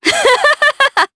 Nicky-Vox_Happy2_jp.wav